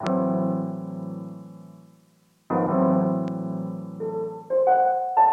爵士钢琴
描述：爵士乐、BOOM BAP、黑暗钢琴
Tag: 90 bpm Boom Bap Loops Piano Loops 918.92 KB wav Key : A FL Studio